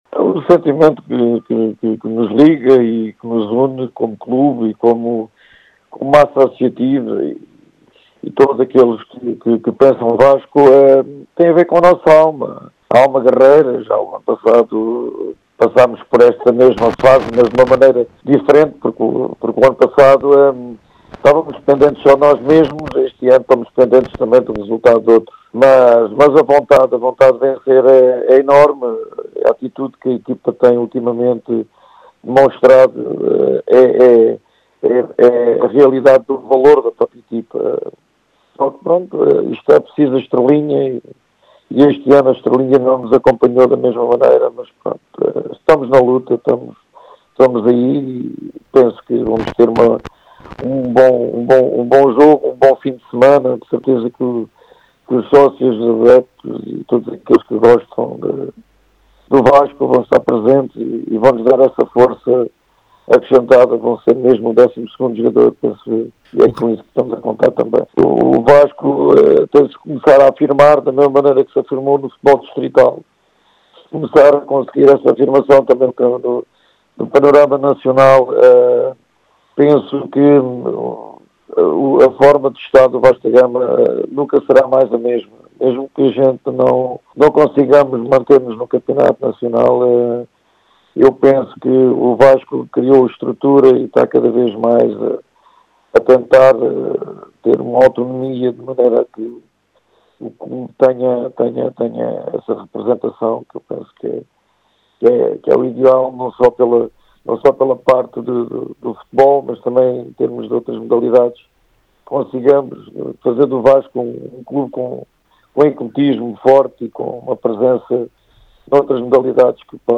Em entrevista à Rádio Vidigueira